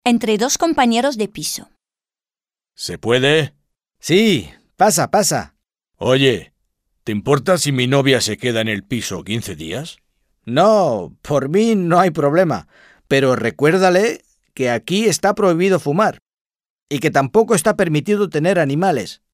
Dialogue - Entre dos compañeros de piso